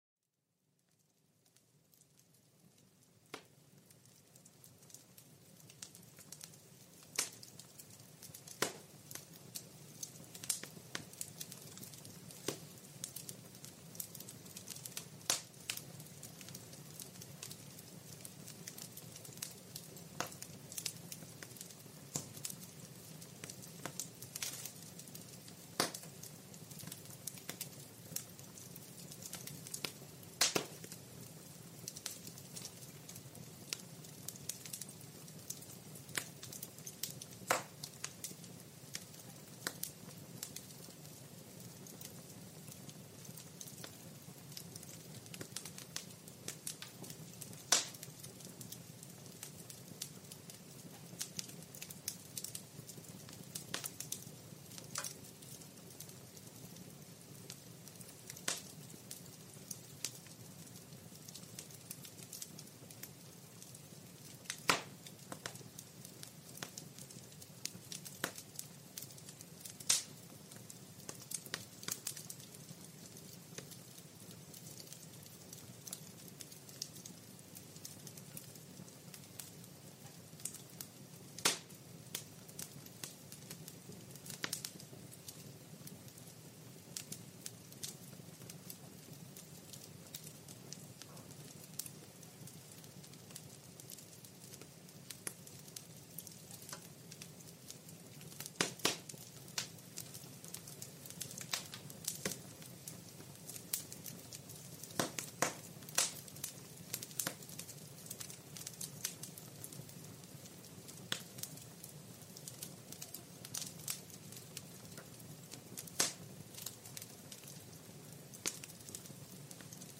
Escucha el relajante crepitar de una chimenea para relajarte y dormir
Sumérgete en la cálida atmósfera de una chimenea con este episodio calmante. El crujido de las llamas y el suave murmullo del fuego te transportarán a un estado de relajación profunda.